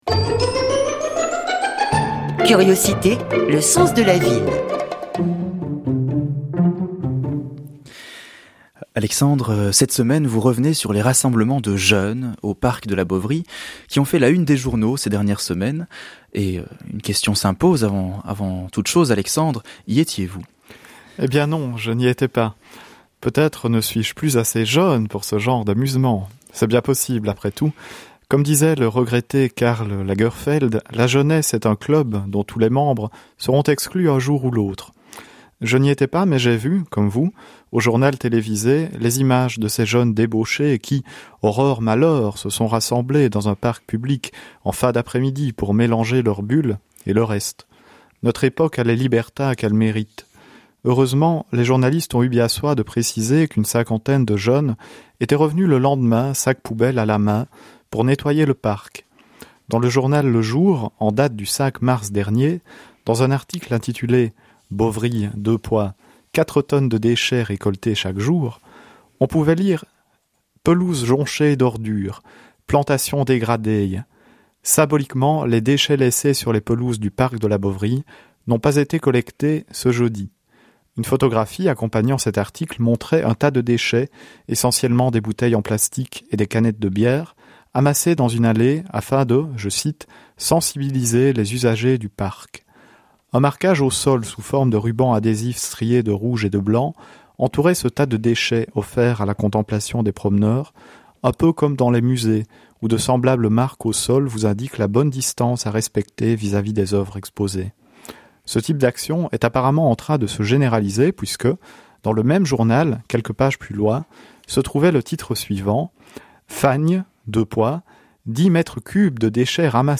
chronique radio